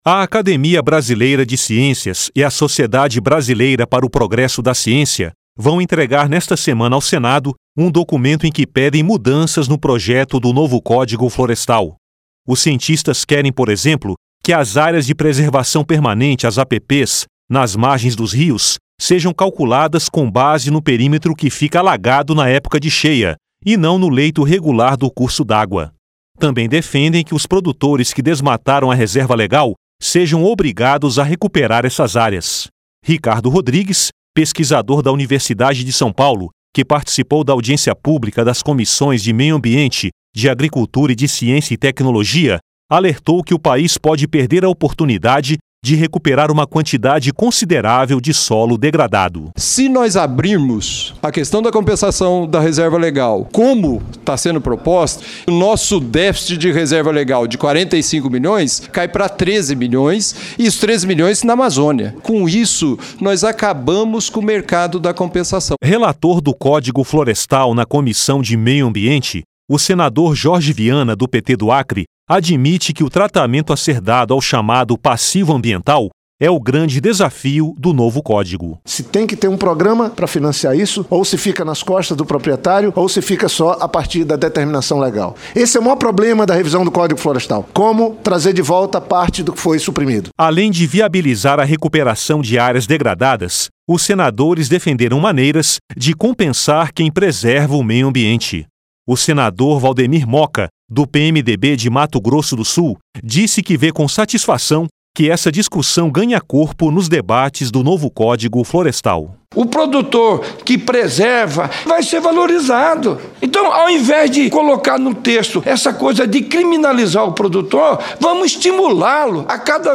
LOC: SENADORES DEFENDEM QUE O NOVO CÓDIGO FLORESTAL DÊ INCENTIVOS A QUEM PRESERVA O MEIO AMBIENTE E AO PRODUTOR QUE RECUPERA ÁREAS DEGRADADAS. LOC: EM DEBATE NESTA TERÇA-FEIRA, ELES OUVIRAM REPRESENTANTES DA COMUNIDADE CIENTÍFICA, QUE ANUNCIARAM A INTENÇÃO DE PEDIR MUDANÇAS NO PROJETO.